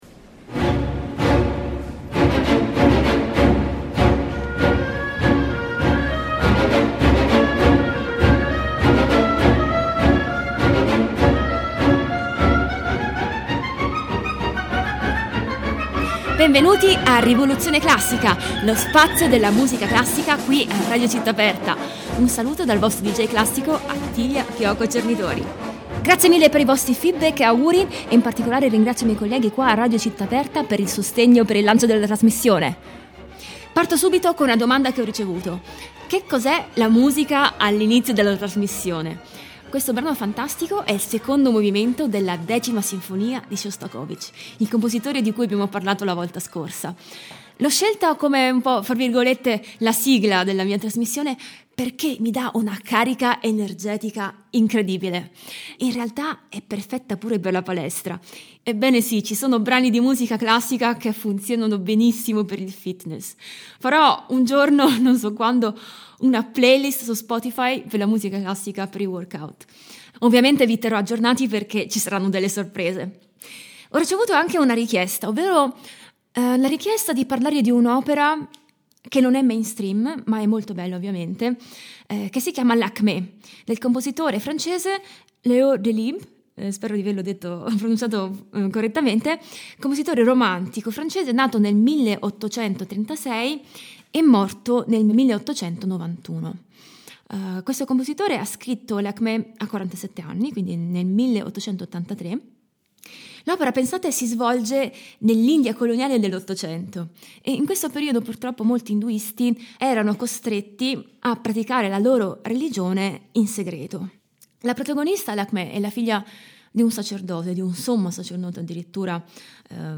Anna Netrebko, soprano Elina Garanca, mezzosoprano
Variazioni per fagotto e pianoforte